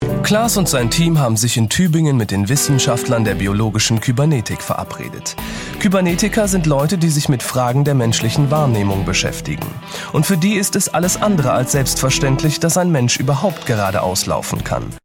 deutscher Sprecher, helle bis mittlere Stimmlage: gerne eingesetzt für Funk- und TV-Werbung, Off-Stimme, Voice Over
Kein Dialekt
Sprechprobe: Werbung (Muttersprache):